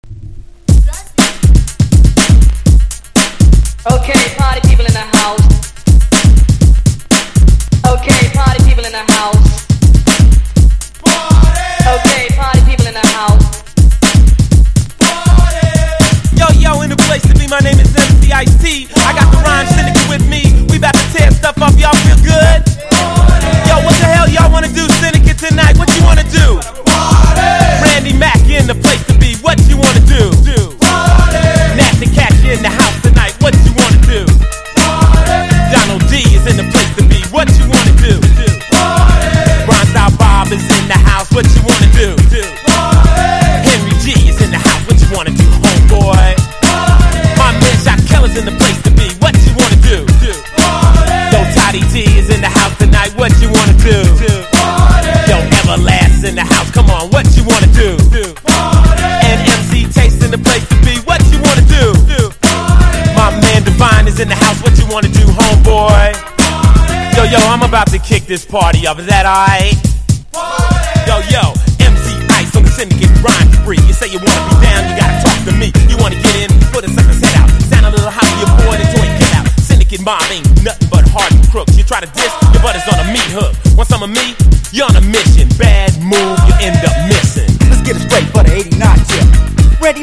シリアス過ぎない「間」の取れたエレクトロビートに非の打ち所が無い走ったラップが乗った名作！